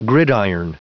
Prononciation du mot : gridiron